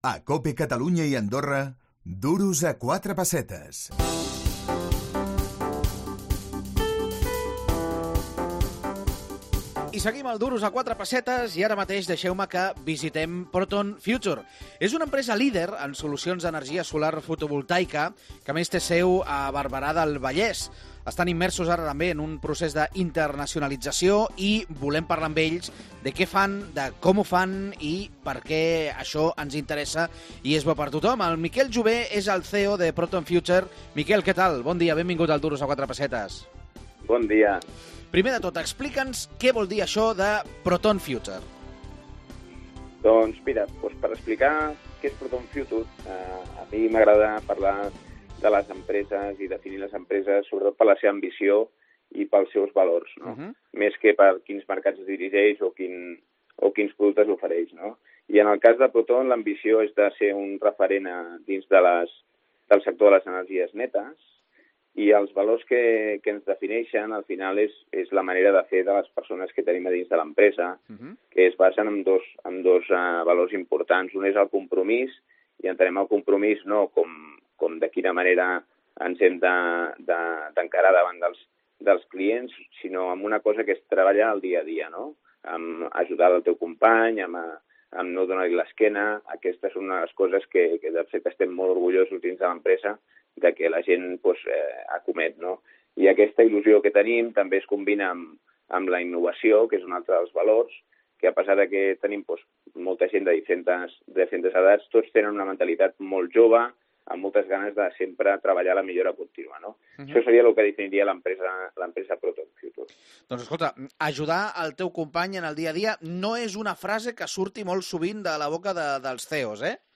L'actualitat econòmica, desde diferents angles, al “Duros a 4 pessetes”, el teu programa econòmic de capçalera. Analitzem totes aquelles notícies que poden afectar a la teva butxaca.